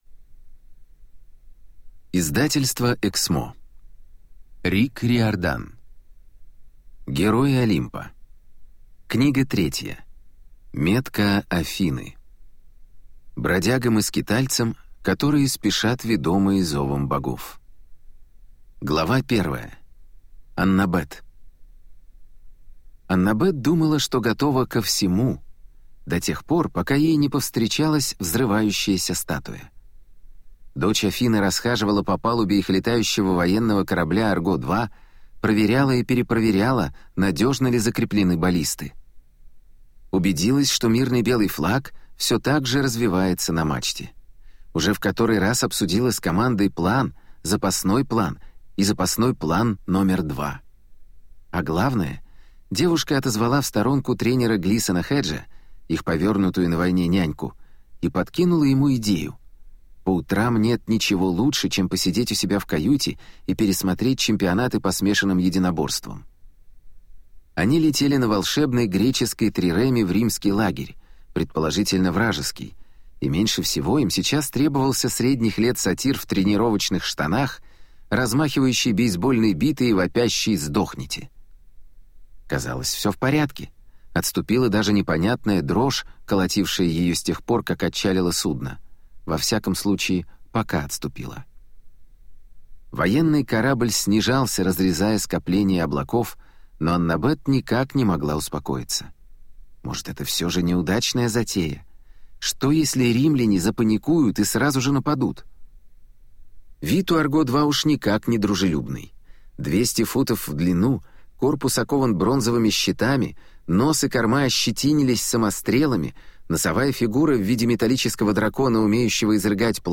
Аудиокнига Метка Афины | Библиотека аудиокниг